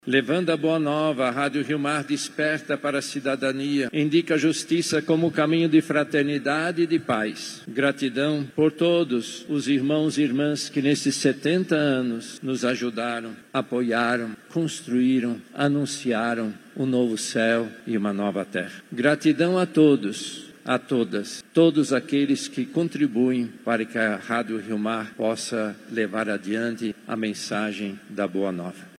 Aniversários da Rádio Rio Mar e Fundação Rio Mar são celebrados com missa especial na catedral
Durante a homilia, o Cardeal Leonardo Steiner expressou gratidão pelas duas instituições e destacou o serviço essencial desenvolvido pelos colaboradores e por quem ajuda a manter esta causa.
SONORA-2-CARDEAL-STEINER.mp3